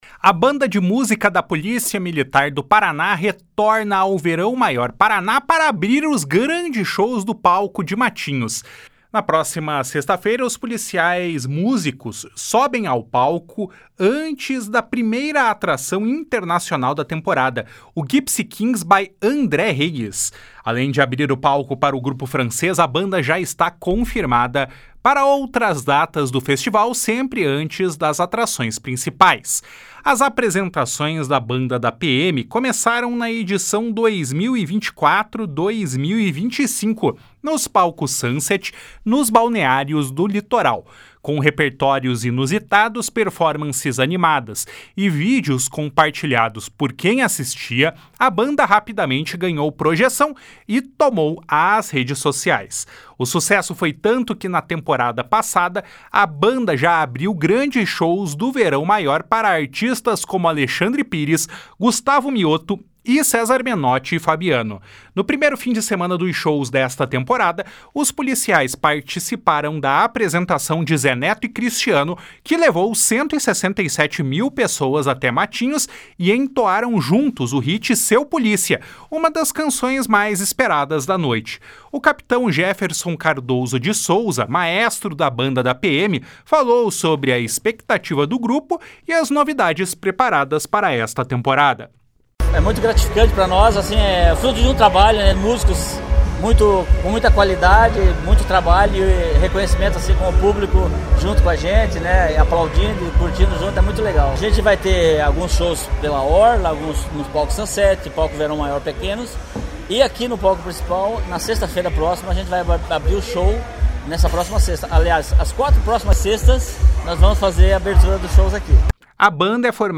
(Repórter: